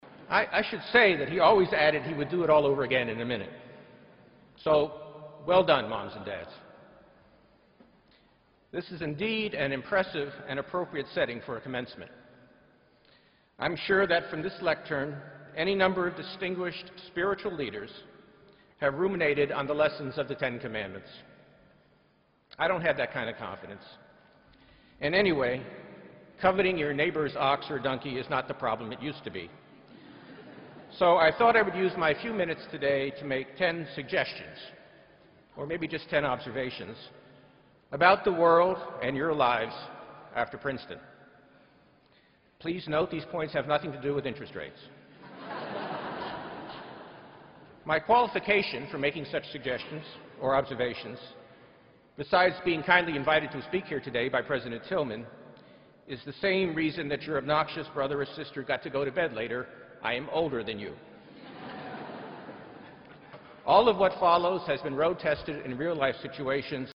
公众人物毕业演讲第298期:本伯南克2013普林斯顿(2) 听力文件下载—在线英语听力室